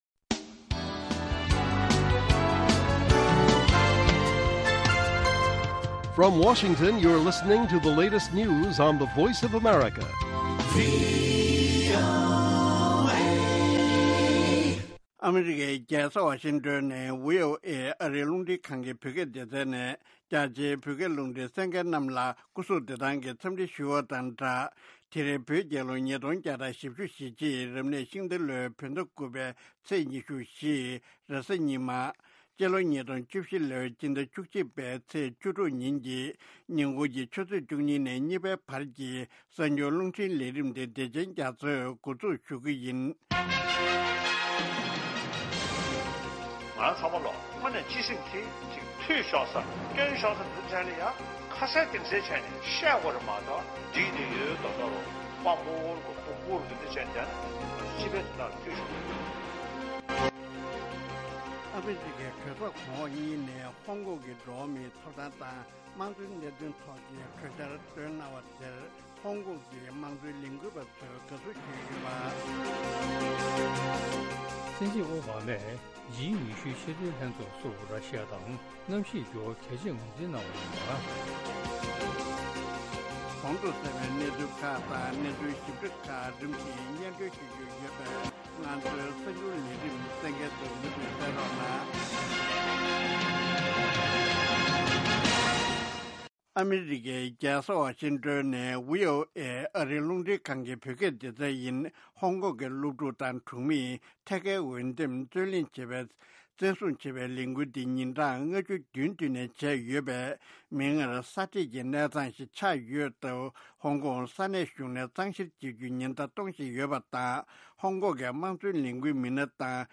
ཉིན་གུང་གི་གསར་འགྱུར།